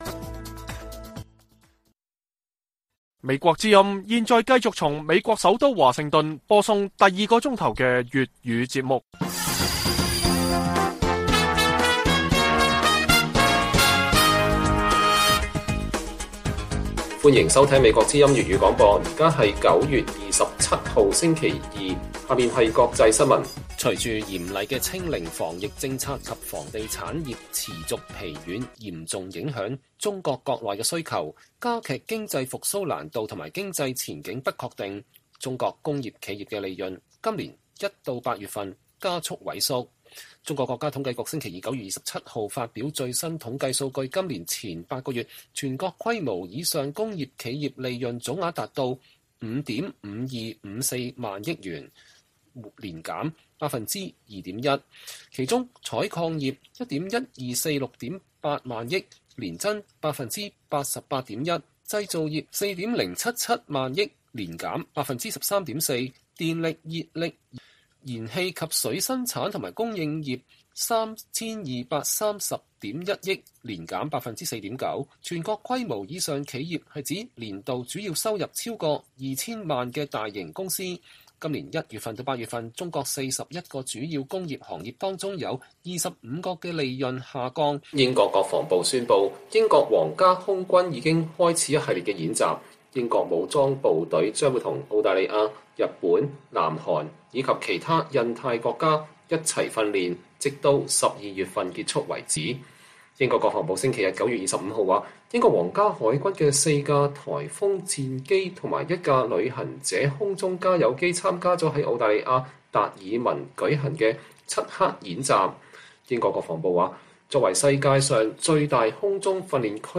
粵語新聞 晚上10-11點: 中國工商業利潤受清零和房地產拖累加速萎縮